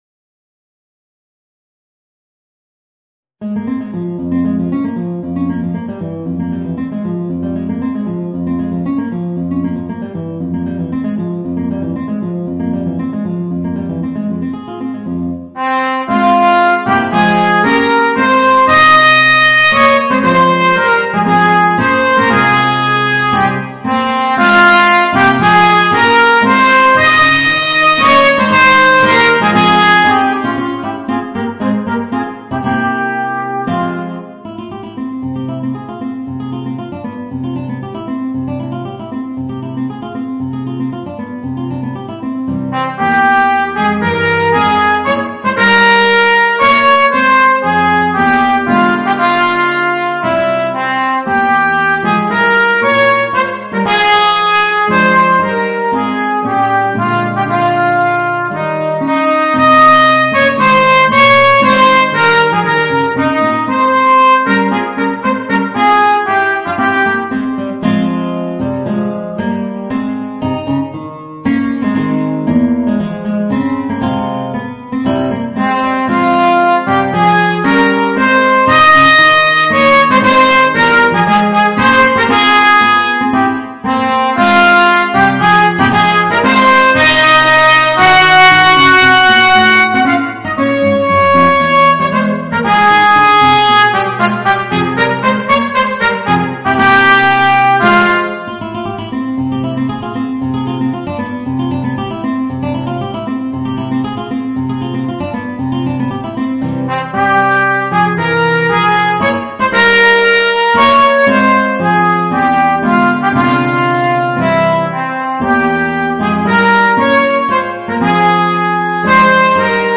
Voicing: Guitar and Trumpet